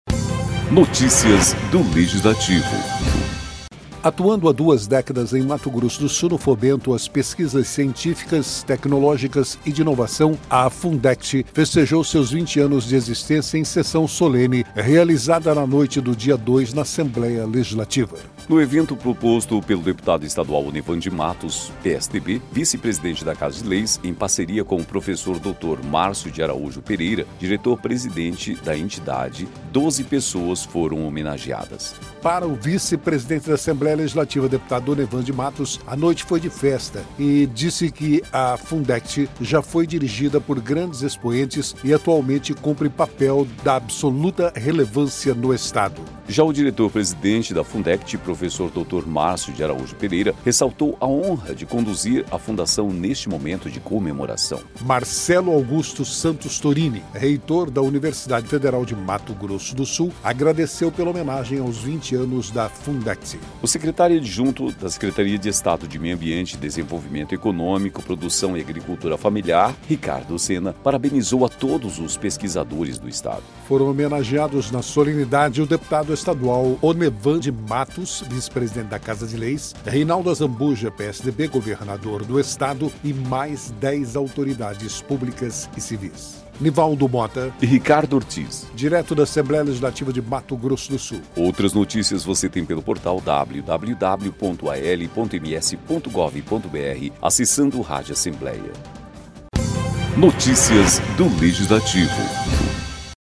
Orgulho e reconhecimento marcam a comemoração dos 20 anos da Fundect